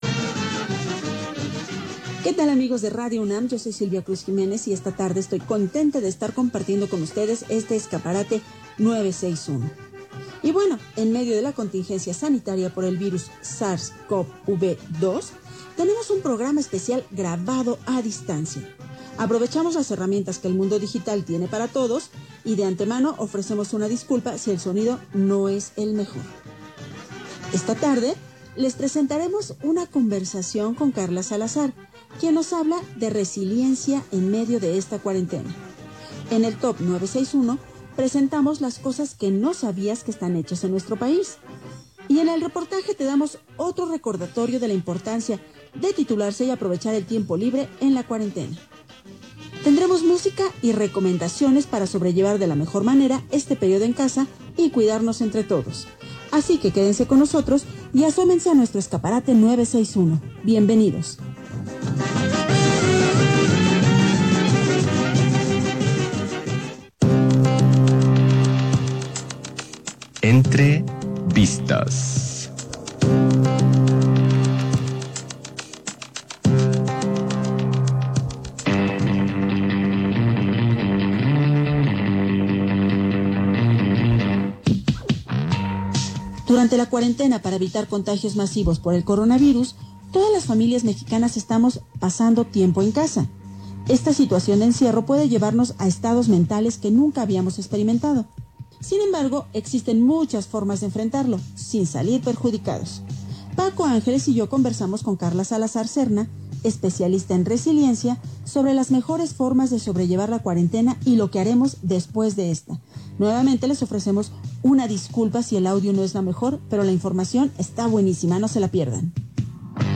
Radio UNAM: Programa Escaparate 961, (8 de mayo del 2020). Entrevista sobre el tema: Resiliencia y covid-19.